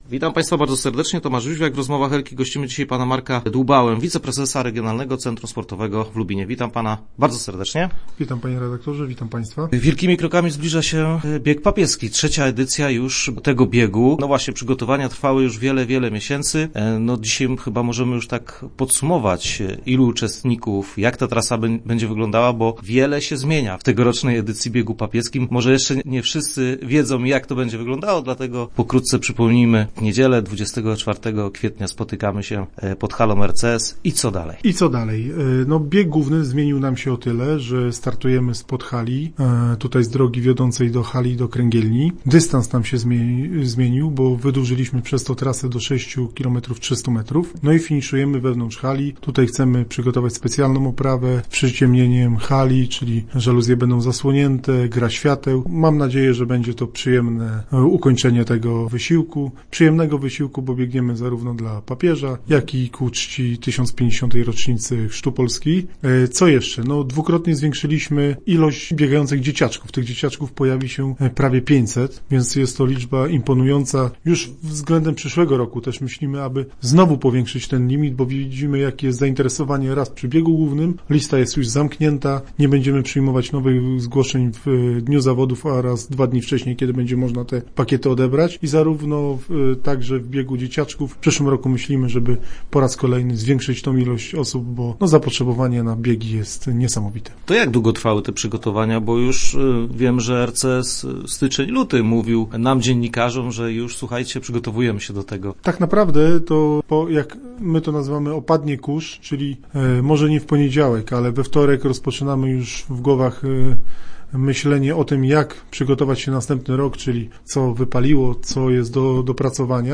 Start arrow Rozmowy Elki arrow Bieg Papieski z rocznicą Chrztu